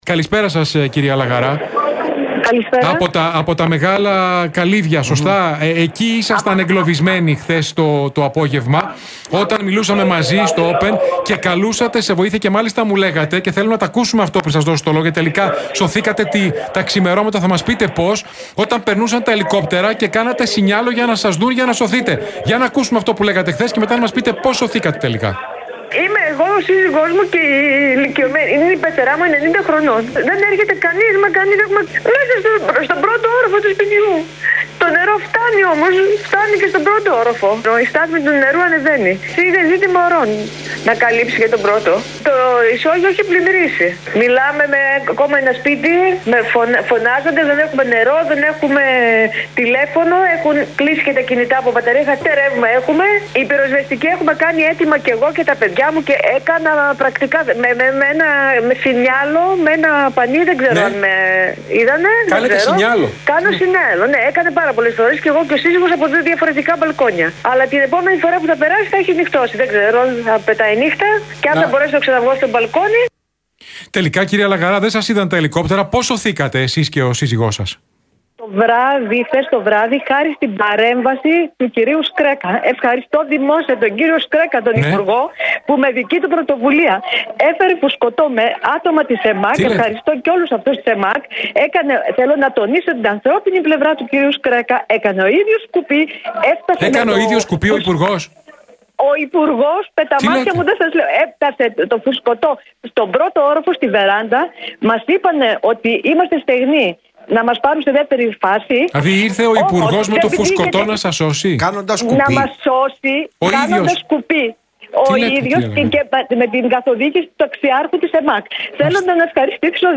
Μεγάλα Καλύβια Τρικάλων: «Μας έσωσε ο κ. Σκρέκας με φουσκωτό κάνοντας ο ίδιος κουπί» - Μαρτυρία κατοίκου στον Realfm